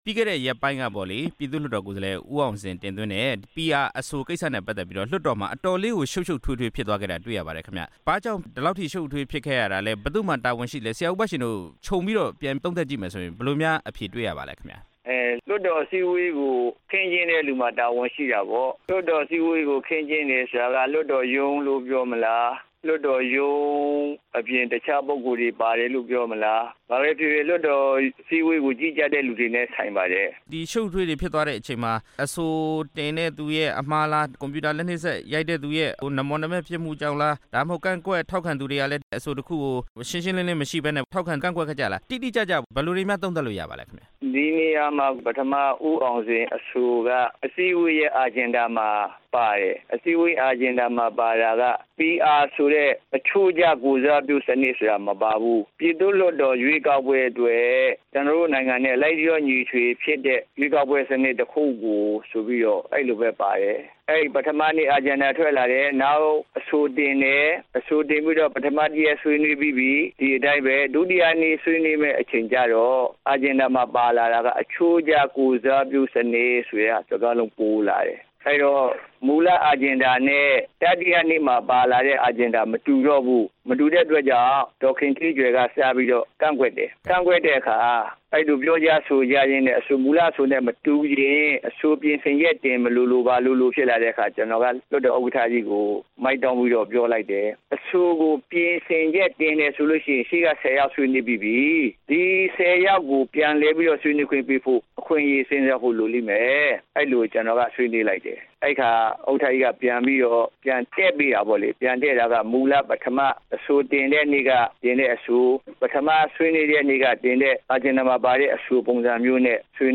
ရွေးကောက်ပွဲစနစ် လေ့လာသုံးသပ်ရေး နဲ့ မြန်မာနိုင်ငံရှေ့ရေး မေးမြန်းချက်